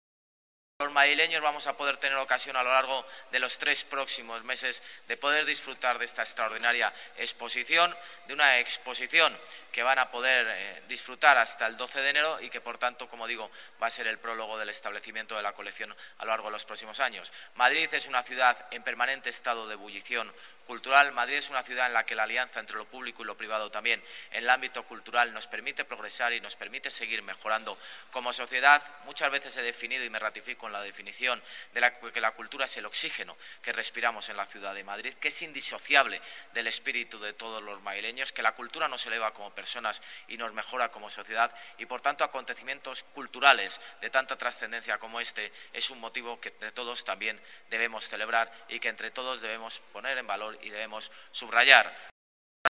Nueva ventana:Declaraciones del alcalde de Madird, José Luis Martínez-Almeida, durante la presentación de la exposición.